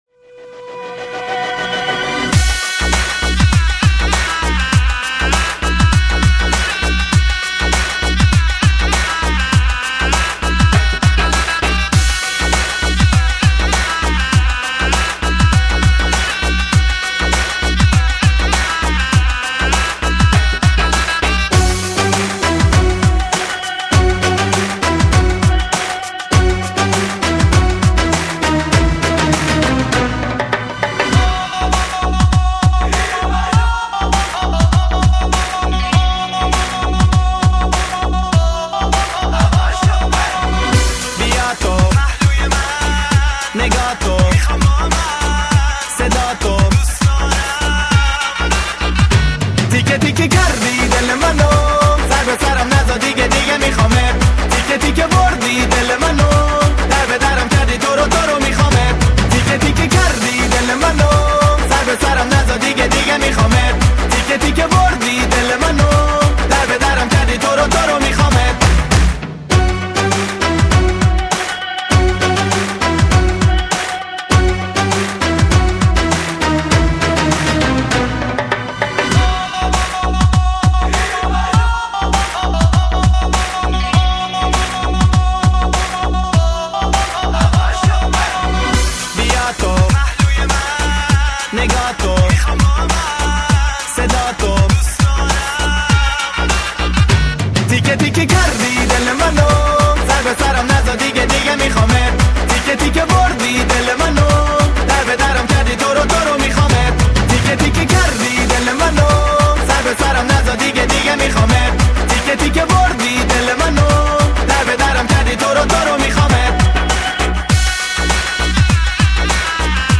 Качество:Ориг+бэк